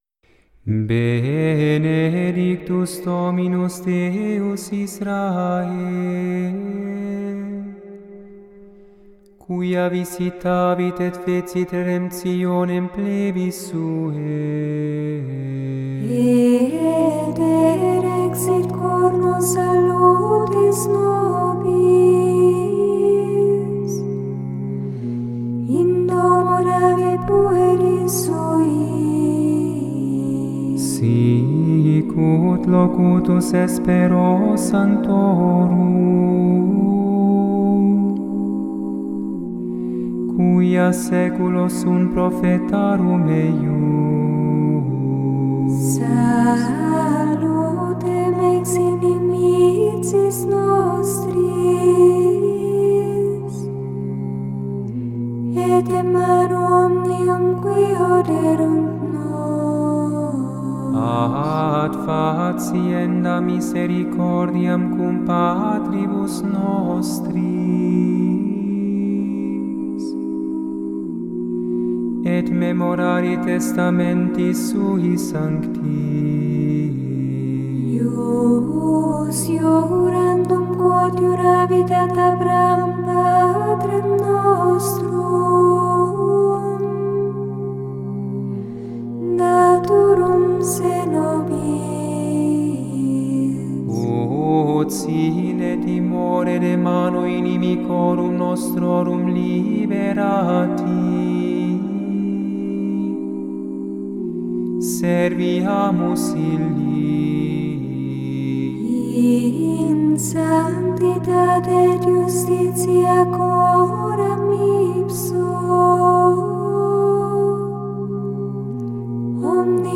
Benedictus (canto gregoriano in latino)
Benedictus_(canto_gregoriano_in_latino)_-_CANTICO_DI_ZACCARIA.mp3